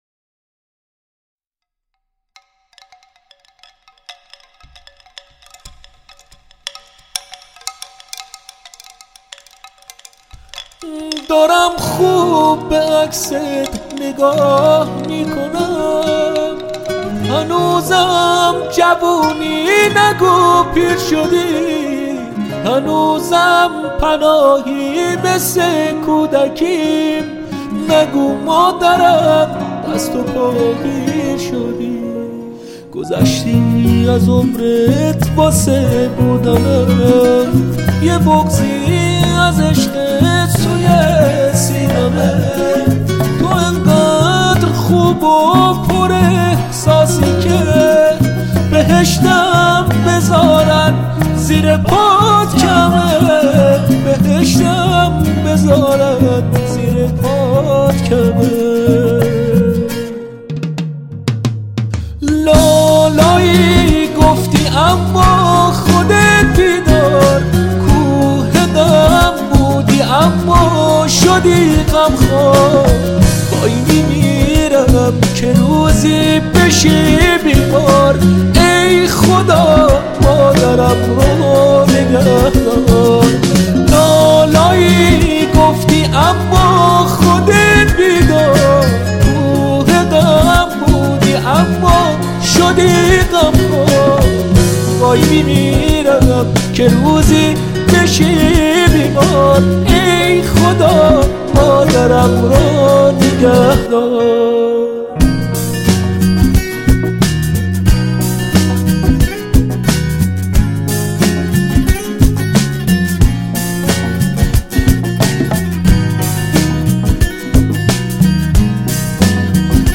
صداش خیلی شبیه معینه…